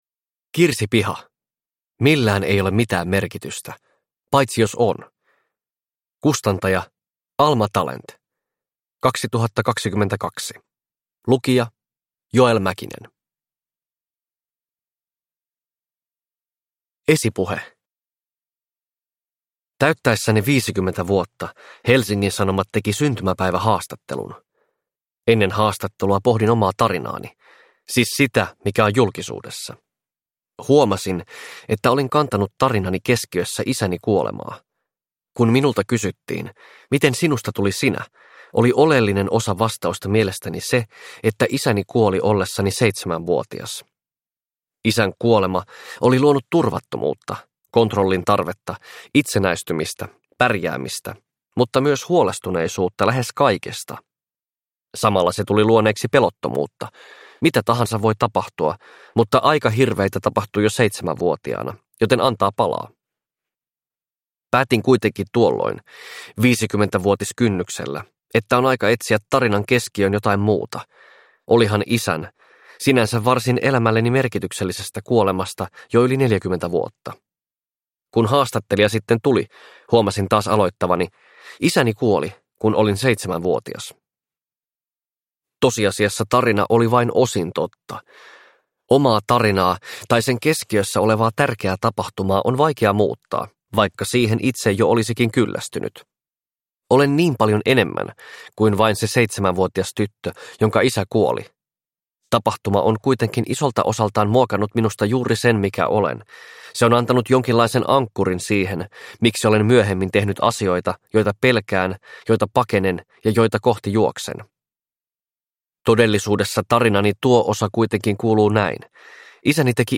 Millään ei ole mitään merkitystä - paitsi jos on – Ljudbok – Laddas ner